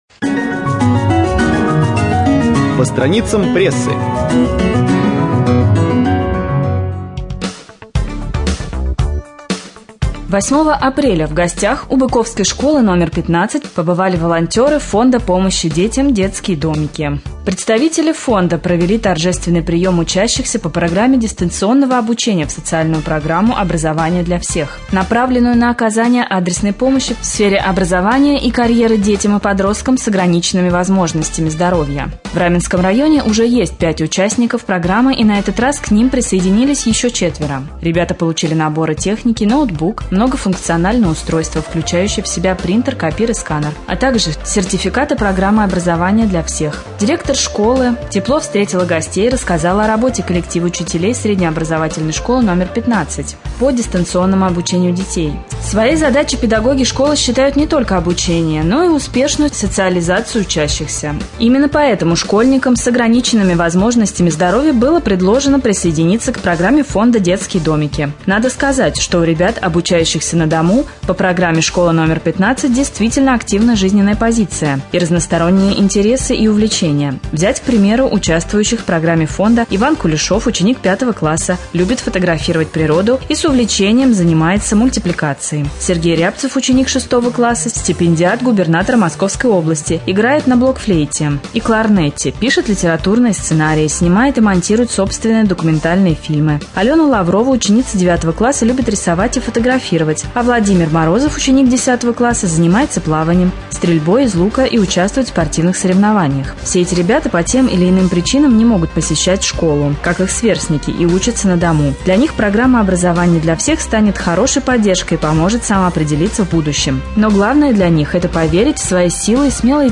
Рубрика «По страницам прессы». Новости читает